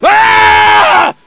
scream06.ogg